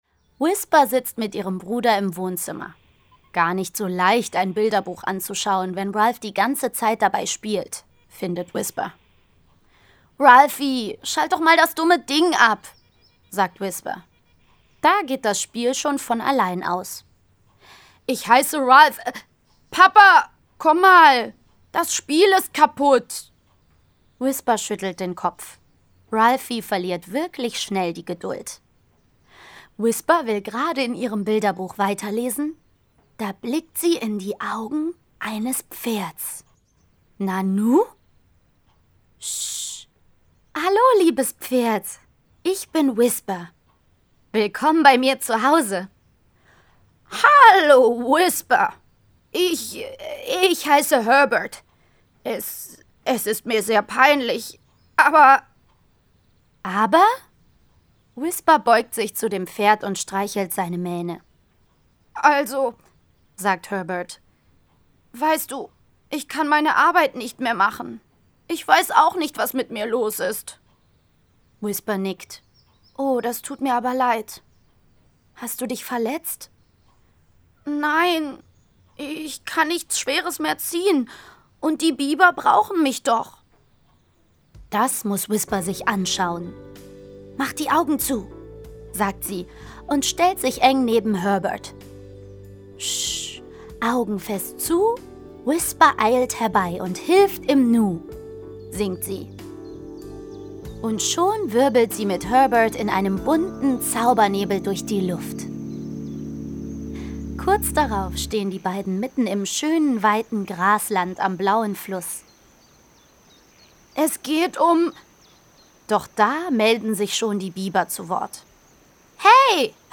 Mit ihren klugen Ideen und ein paar tierischen Freunden an der Seite löst sie jedes noch so große Problem und weiß immer Rat!Dieses Hörbuch mit Musik und Geräuschen enthält die Episoden:-Stark wie ein Pferd-Ein Eisbär lernt schwimmen-Orang Utan ist der Beste!